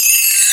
BELLTREE.WAV